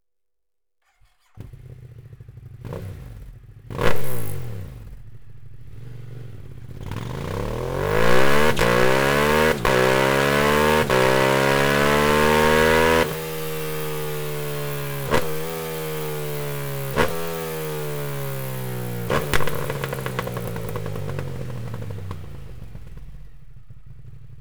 Akrapovic Slip-On Line (Titanium) Endschalldämpfer mit Titan-Hülle in schwarz und Carbon-Endkappe, ohne Straßenzulassung; für Ducati
Mit dem Akrapovic Slip-On System bekommt Ihr Scooter diesen unverkennbaren tiefen und satten Akrapovic Sound, und auch das äußere Erscheinungsbild ändert sich dramatisch.
Sound Akrapovic Slip-On